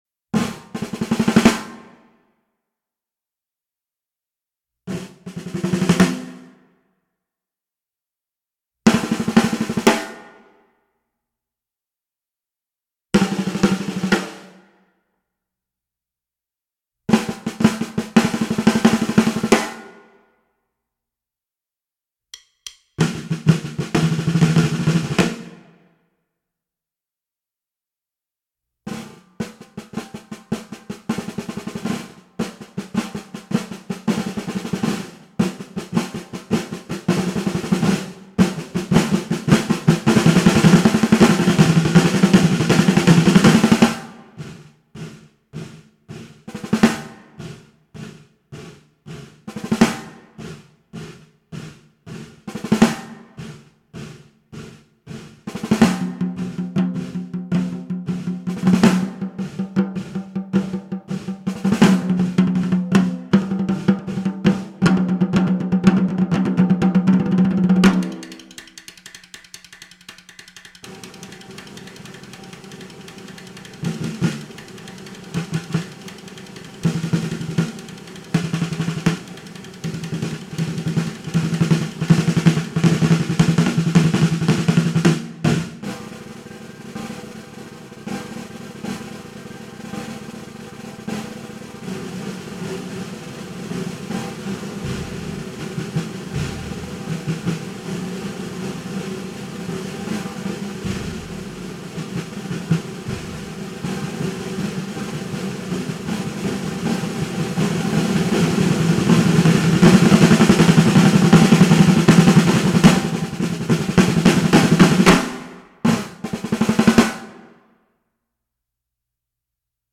Voicing: Percussion Ensemble